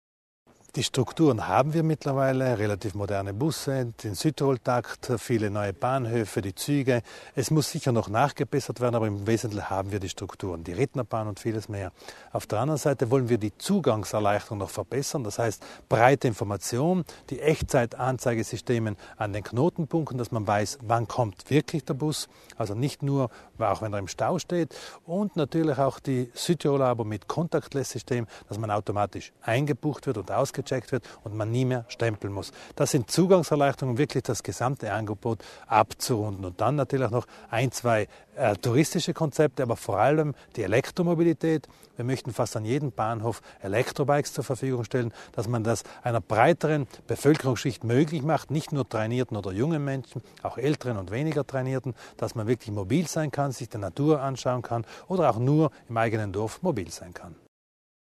Landesrat Widmann über die Herausforderungen der Mobilität
Wer glaubt, die Entwicklung des Angebots im öffentlichen Nahverkehr Südtirols sei zu Ende, den hat Mobilitätslandesrat Thomas Widmann heute (26. Juli) im Gespräch zur Legislatur-Halbzeit eines besseren belehrt.